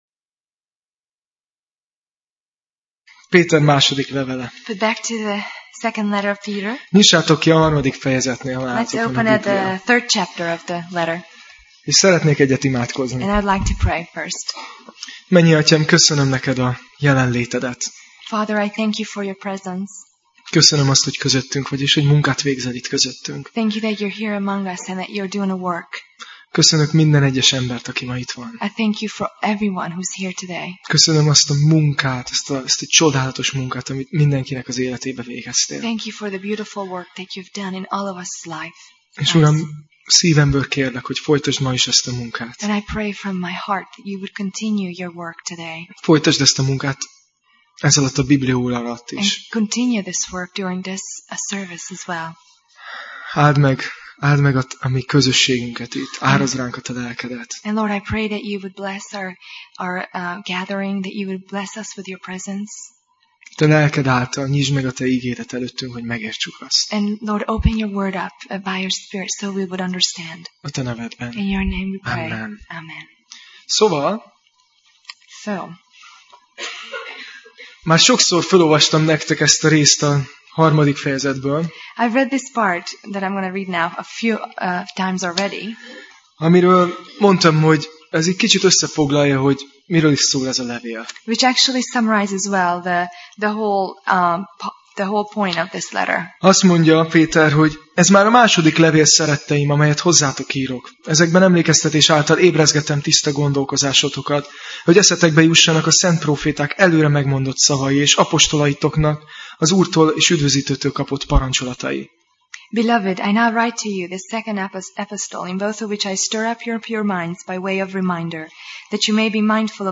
2Péter Passage: 2Péter (2 Peter) 3 Alkalom: Vasárnap Reggel